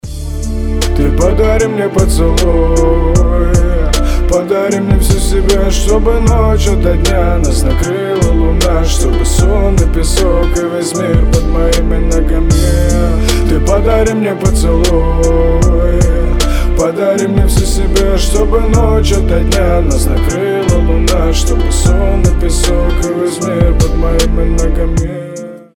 • Качество: 320, Stereo
лирика
русский рэп
романтичные
приятный мужской голос